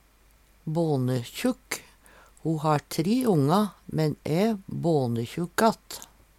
bånekjukk - Numedalsmål (en-US)